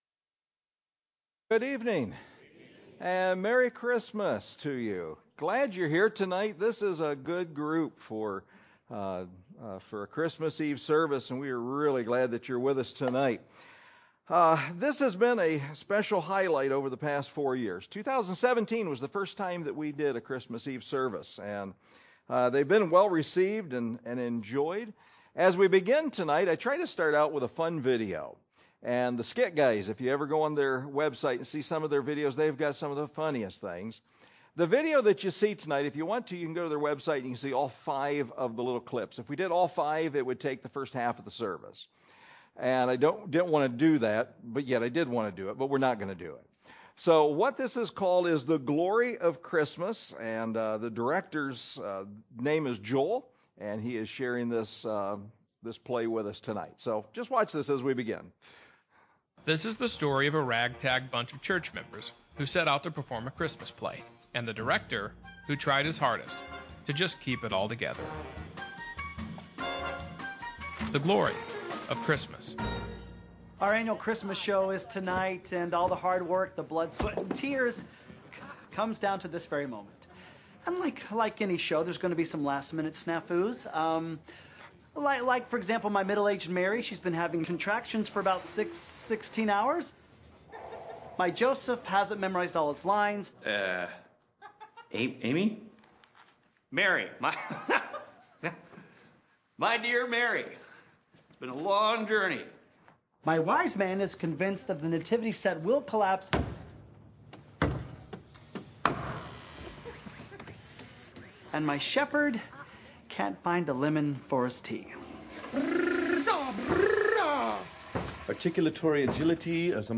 Christmas-Eve-Service-CD.mp3